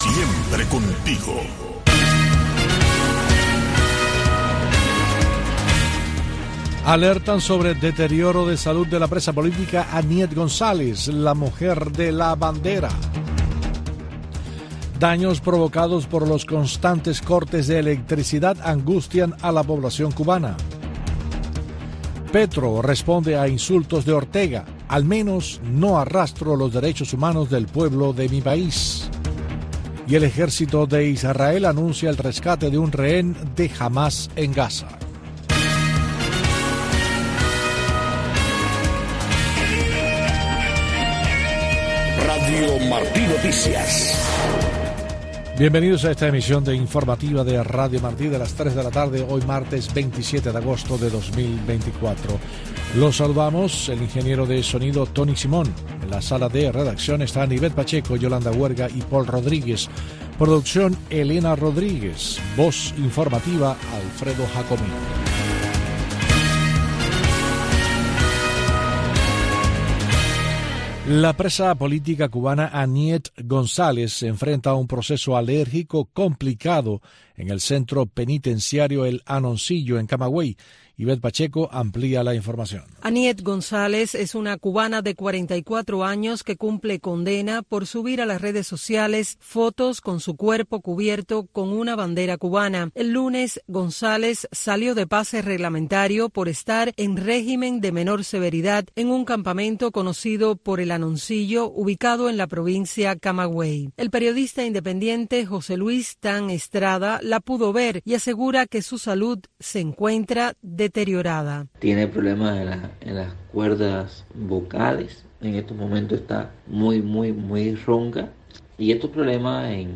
Noticiero de Radio Martí 3:00 PM